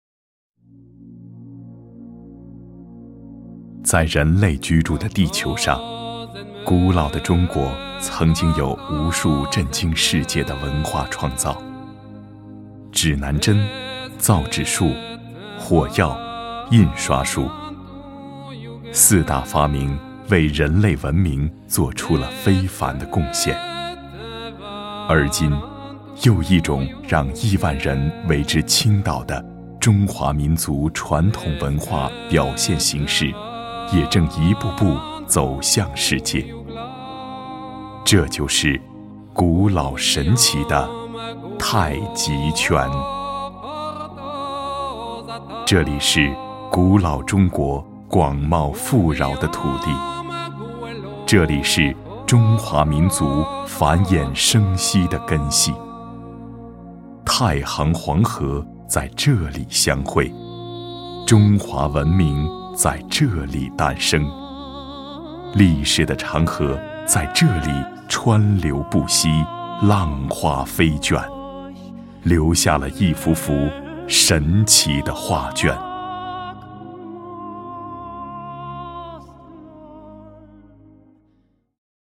Warm, bright, compellent, smooth, professional.
Sprechprobe: Industrie (Muttersprache):